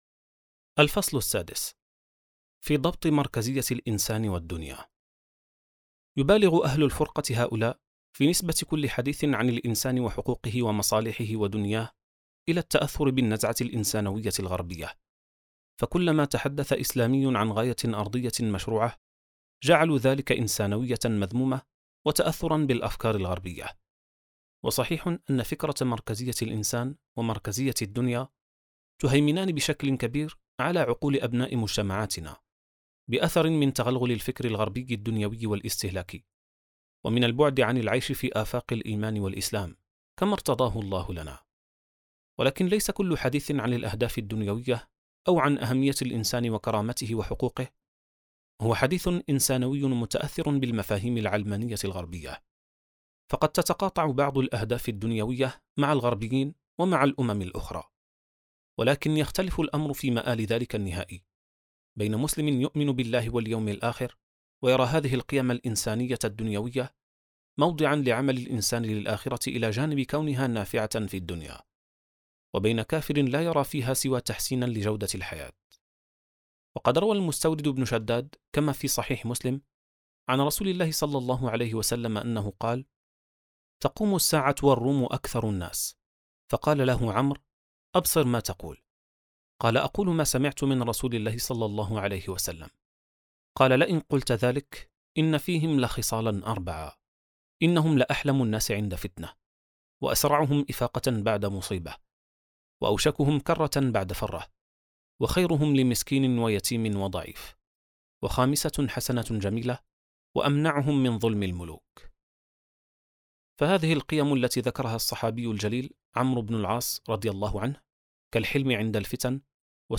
كتاب صوتي | العقائدية القاصرة (78): الفصل السادس • السبيل
كتاب "العقائدية القاصرة" للكاتب شريف محمد جابر وبصوت منصة منطوق (78): الفصل السادس: في ضبط مركزية الإنسان والدنيا.